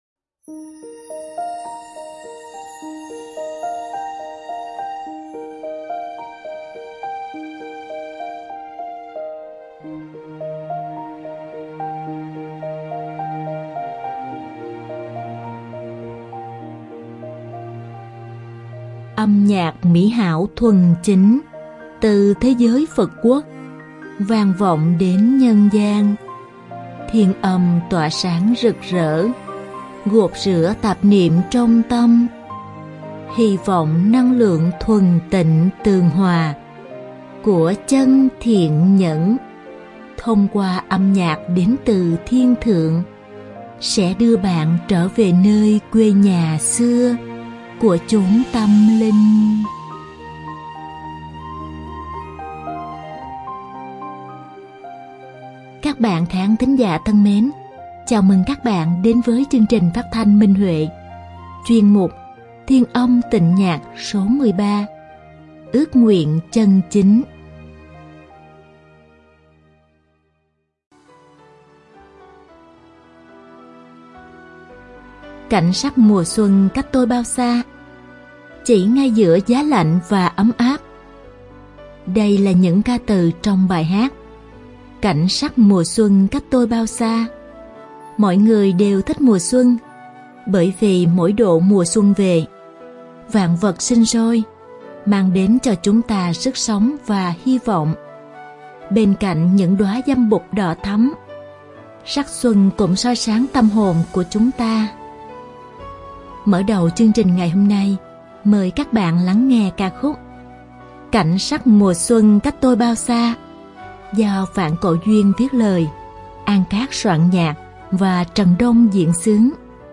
Đơn ca nam
Đơn ca nữ